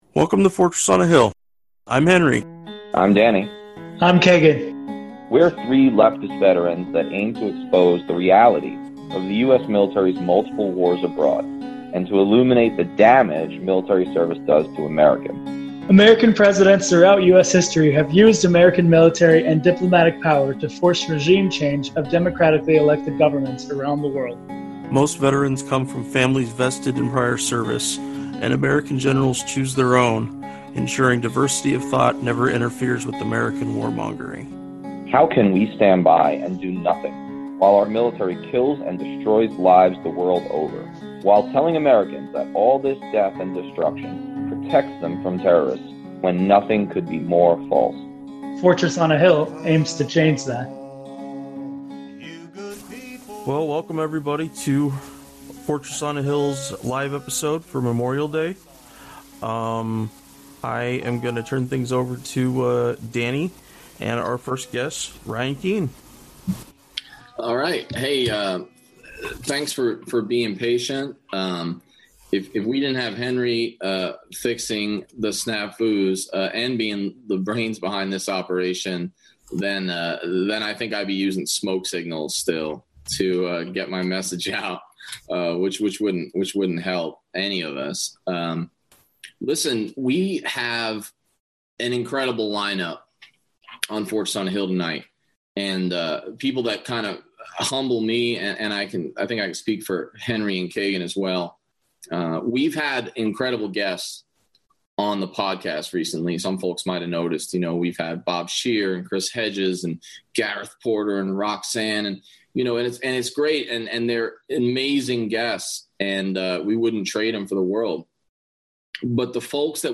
Memorial Day live stream (audio only) – Ep 69